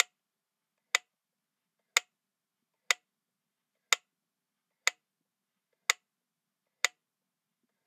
Metronome Slow.wav